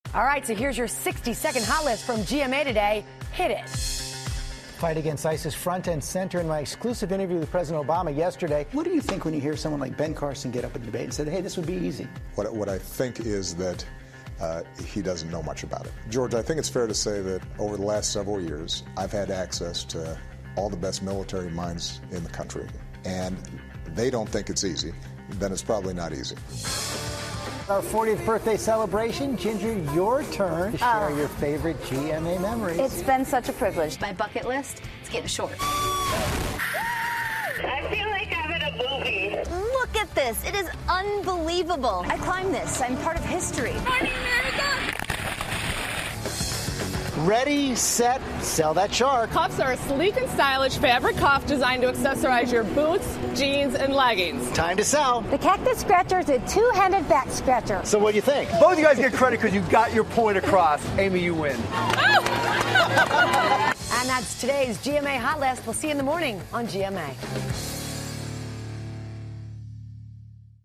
访谈录 奥巴马总统参加乔治·斯特凡诺普洛斯节目 听力文件下载—在线英语听力室